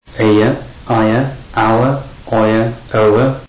Since triphthongs are combinations of closing diphthongs + shwa, they exhibit similar final patterns to the centring diphthongs, while there initial patterns look like closing ones.
triphthongs.
triphthongs.mp3